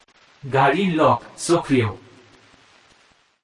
Tesla Lock Sound Indian Bangla Man
Indian male voice saying
(This is a lofi preview version. The downloadable version will be in full quality)
JM_Tesla-Lock_Bangla_Man_Watermark.mp3